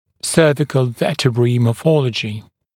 [‘sɜːvɪkl ‘vɜːtɪbriː mɔː’fɔləʤɪ][‘сё:викл ‘вё:тибри: мо:’фолэджи]морфология шейных позвонков